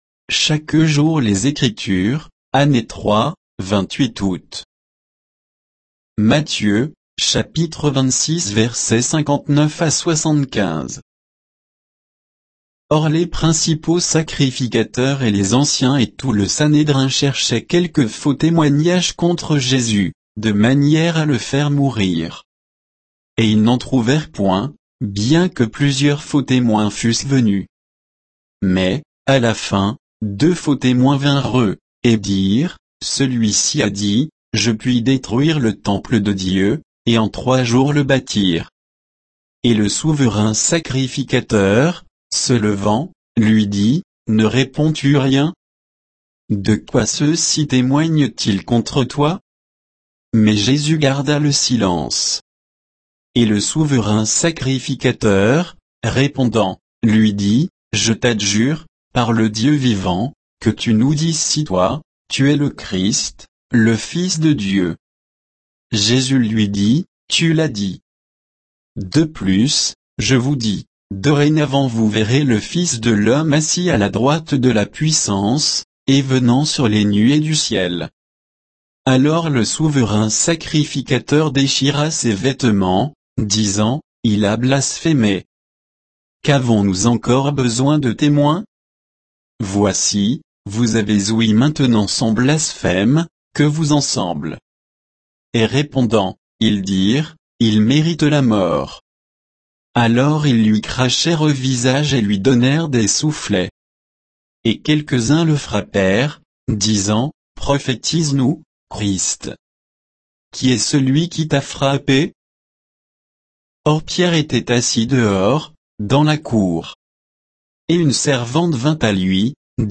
Méditation quoditienne de Chaque jour les Écritures sur Matthieu 26, 59 à 75